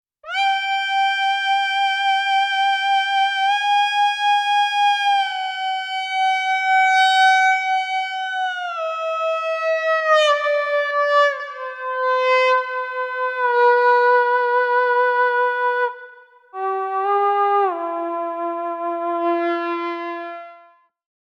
No other effects processing was used other than some normalization, and each example is a single track. All effects and pitch modulation were improvised using the FLUX bows.
AG-Delayed-pitch01.mp3